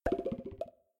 gameover.ogg